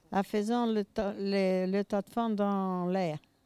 Localisation Barbâtre
Catégorie Locution